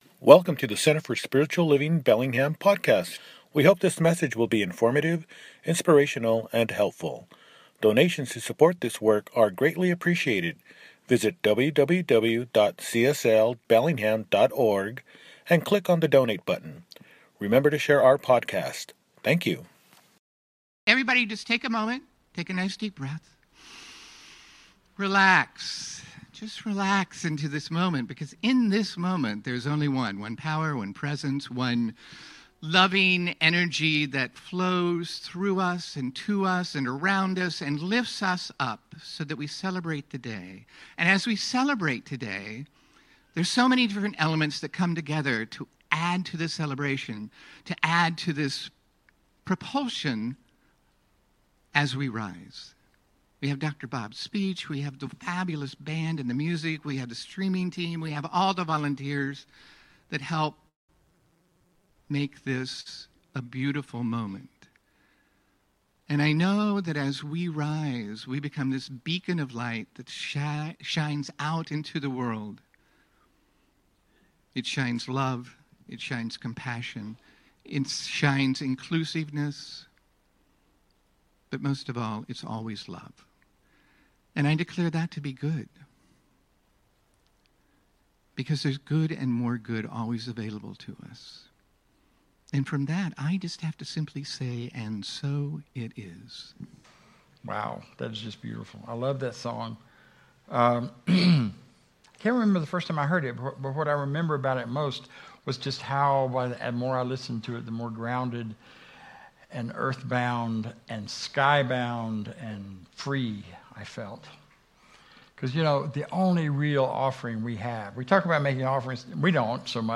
Where are You Going? – Celebration Service